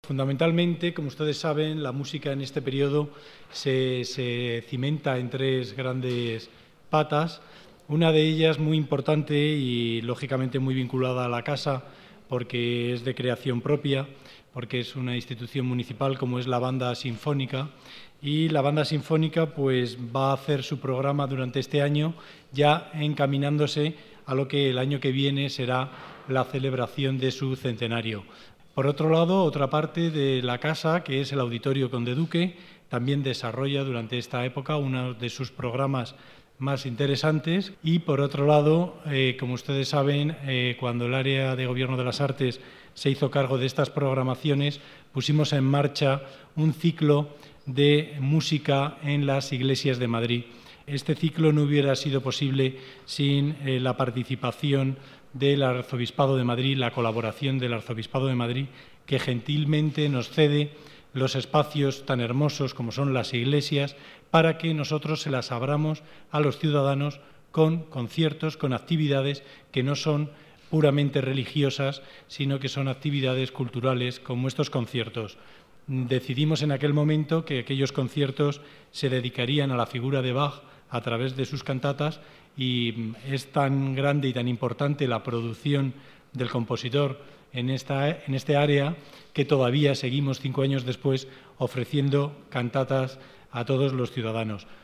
Nueva ventana:Declaraciones de Manuel Lagos, director general de Actividades Culturales de Las Artes, durante la presentación de "Músicas de Otoño"